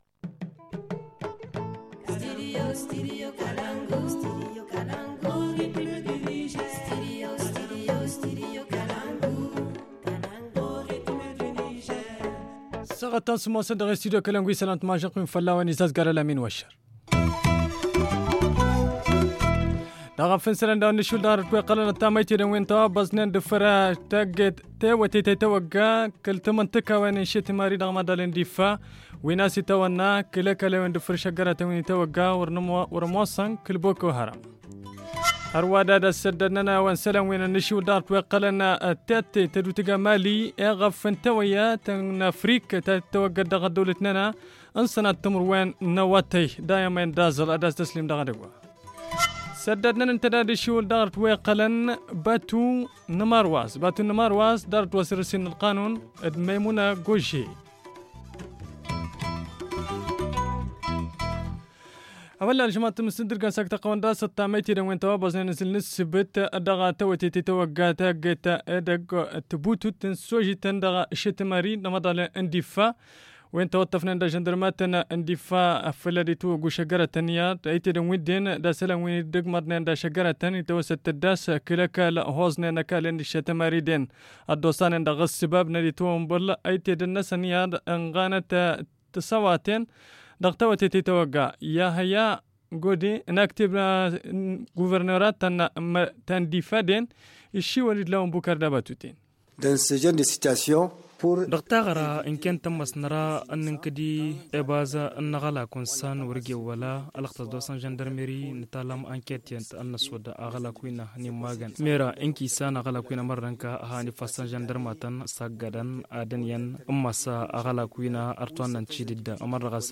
Le journal du 18 février 2019 - Studio Kalangou - Au rythme du Niger